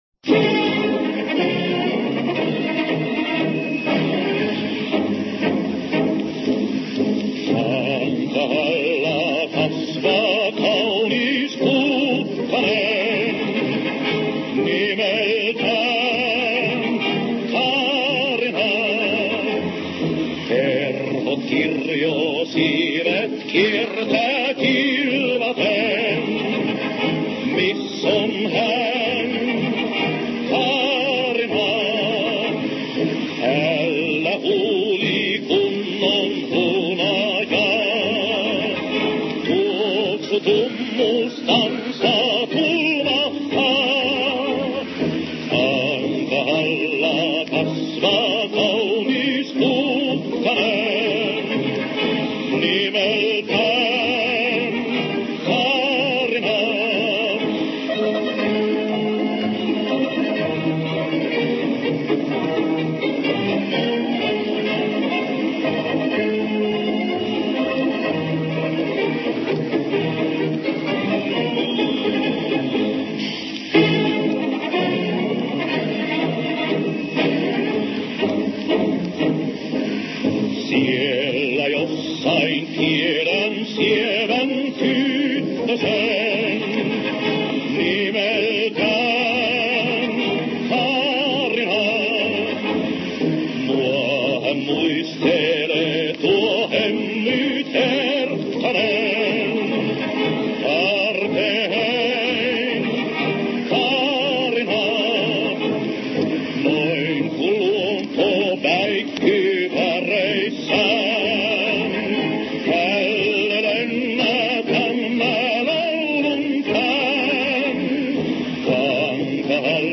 Послушайте вот такое (сольное) исполнение песни «Kaarina» — финской «Эрики»:
Понятия не имею, о чём столь вдохновенно поёт тут неизвестный мне певец…